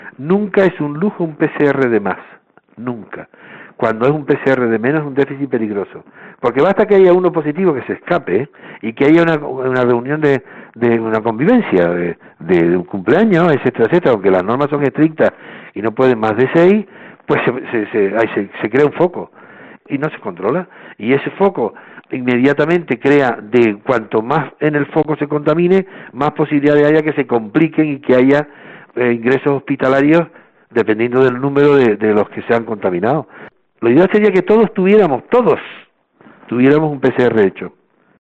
Antonio Alarcó, portavoz de Sanidad del PP en el Senado
El senador del Partido Popular y jefe de Cirugía del HUC, Antonio Alarcó, ha planteado en COPE la necesidad de extender los cribados de coronavirus a toda la población de Tenerife para frenar el avance de la pandemia en la isla.